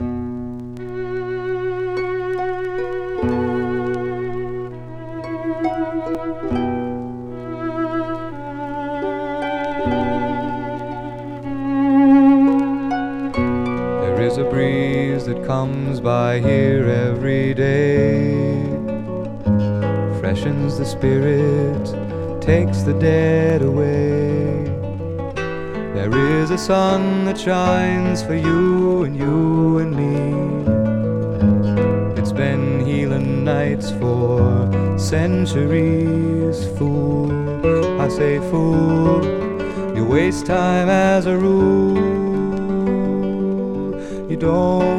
Folk, Rock, Pop, SSW　USA　12inchレコード　33rpm　Stereo